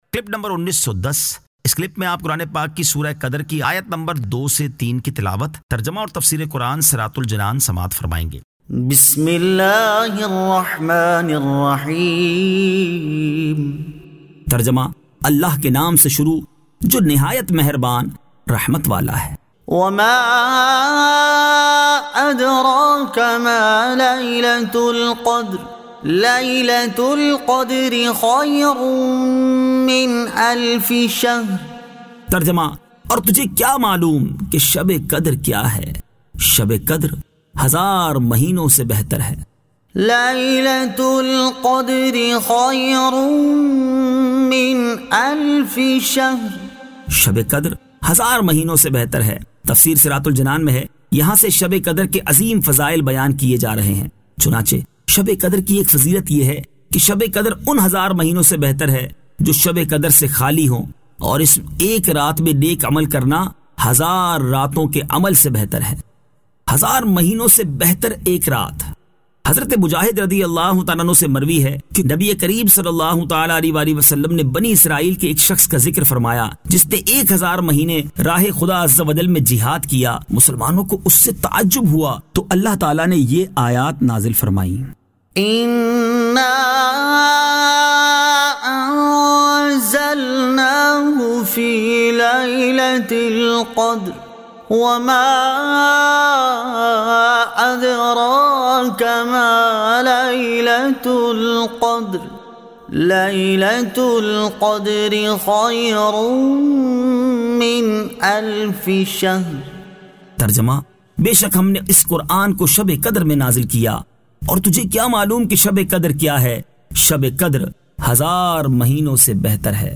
Surah Al-Qadr 02 To 03 Tilawat , Tarjama , Tafseer